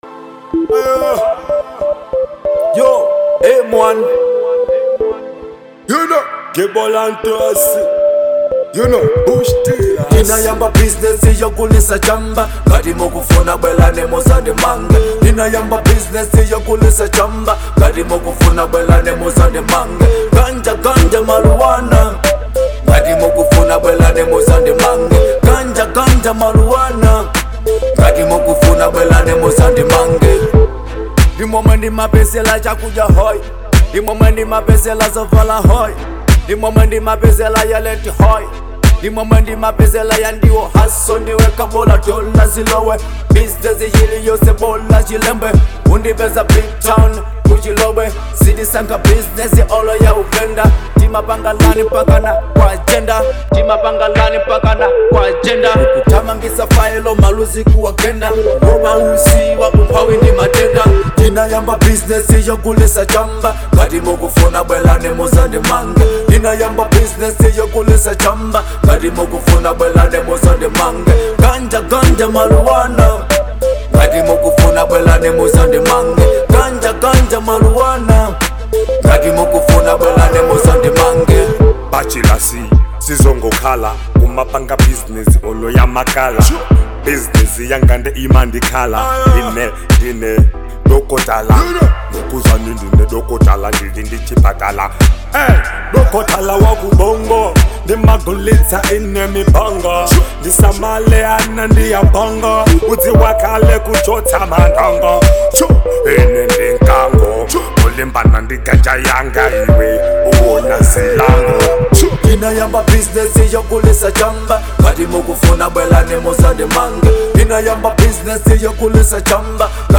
Genre : Hip hop/Dancehall